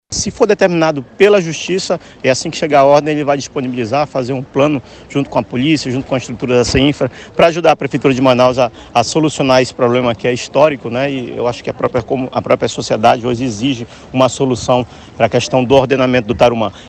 A Polícia Militar deve ser acionada para cumprir a decisão, como explica o vice-governador do Amazonas, Tadeu de Souza.